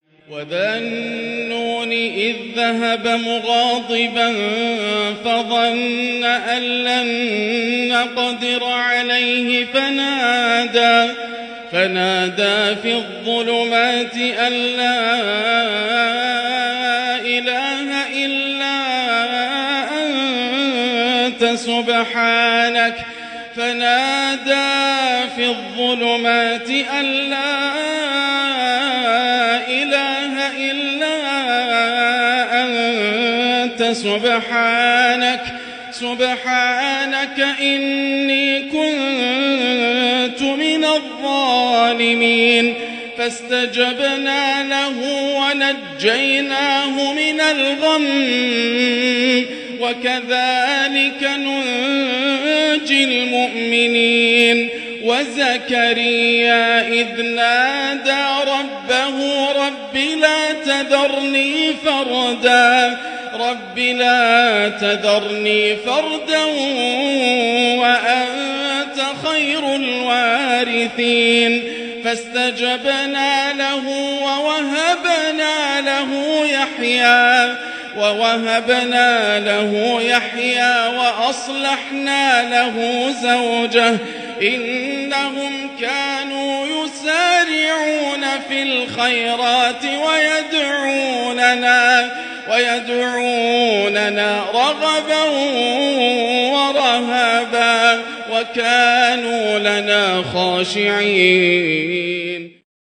“فنادى في الظلمات” تلاوة عجيبة لا توصف للآسر د.ياسر الدوسري > مقتطفات من روائع التلاوات > مزامير الفرقان > المزيد - تلاوات الحرمين